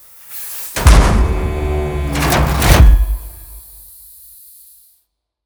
shieldsoff2.wav